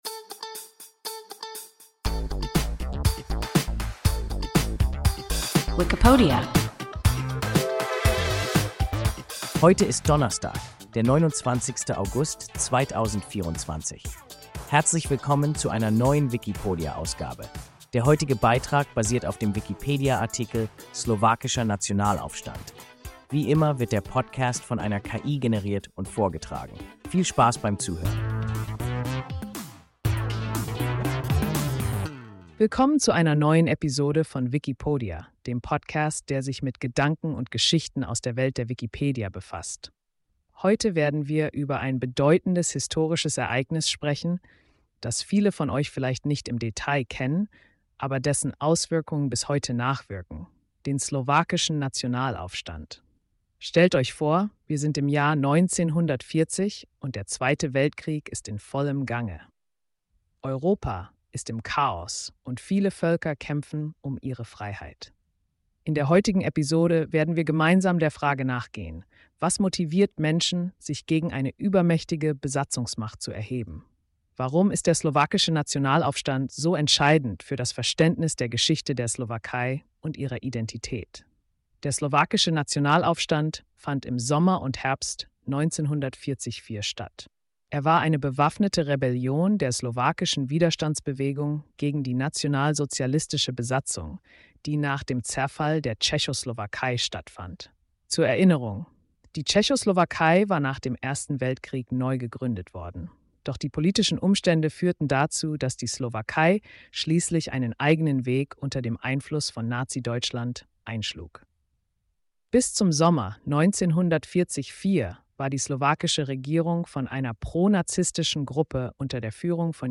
Slowakischer Nationalaufstand – WIKIPODIA – ein KI Podcast